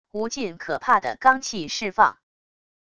无尽可怕的罡气释放wav音频